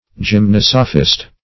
Gymnosophist \Gym*nos"o*phist\ (j[i^]m*n[o^]s"[-o]*f[i^]st), n.